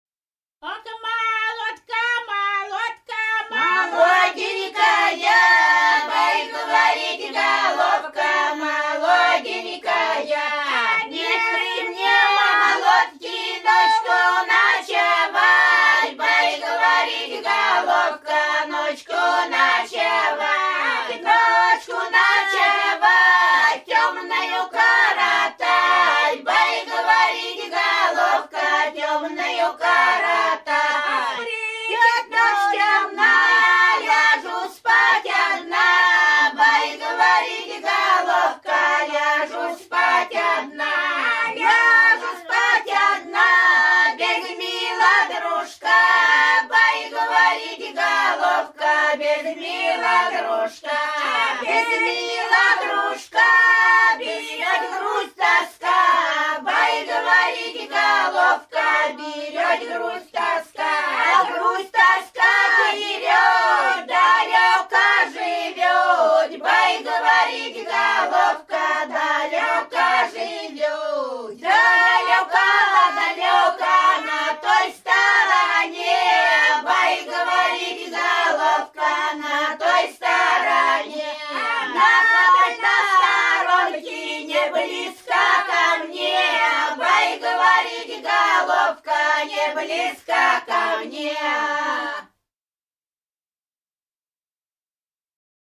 Рязань Кутуково «Ох, молодка, молодка», плясовая.